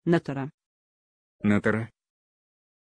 Pronunciation of Natyra
pronunciation-natyra-ru.mp3